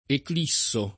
eclissare v.; eclisso [ ekl &SS o ]